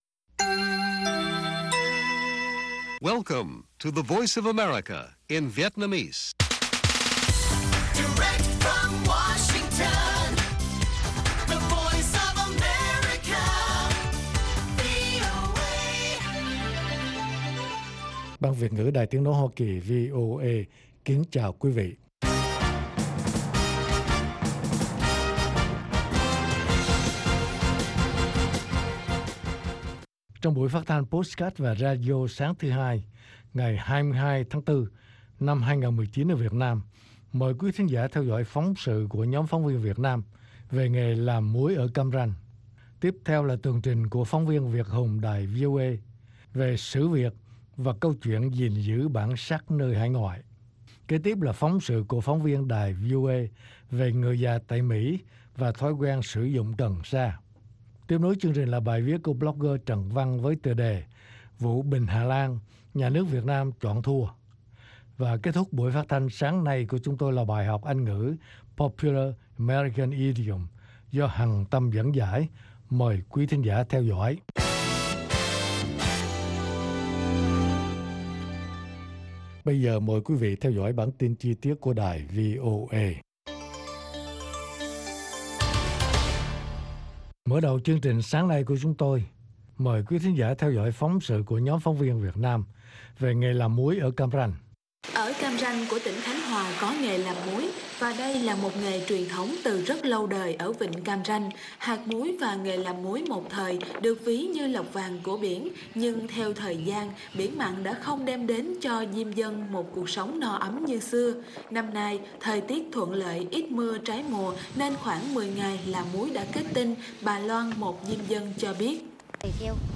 Tin tức luôn cập nhật, thời sự quốc tế, và các chuyên mục đặc biệt về Việt Nam và thế giới. Các bài phỏng vấn, tường trình của các phóng viên VOA về các vấn đề liên quan đến Việt Nam và quốc tế.